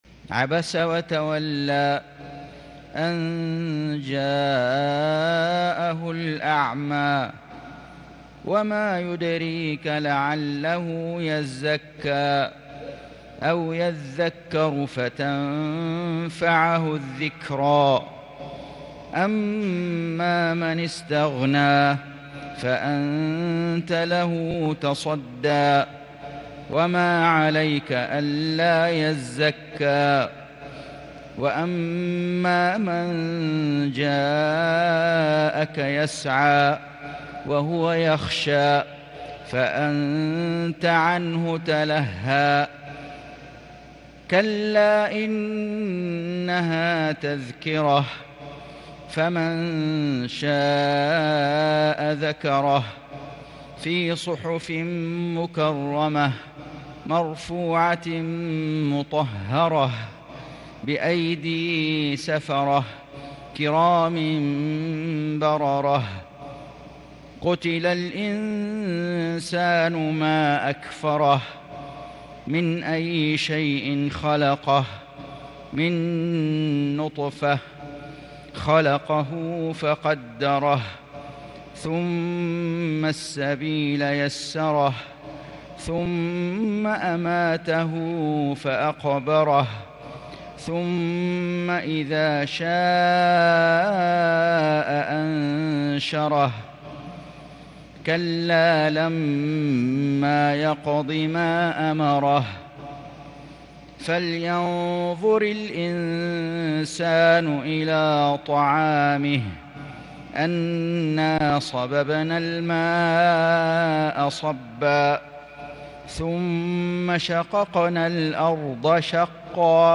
سورة عبس > السور المكتملة للشيخ فيصل غزاوي من الحرم المكي 🕋 > السور المكتملة 🕋 > المزيد - تلاوات الحرمين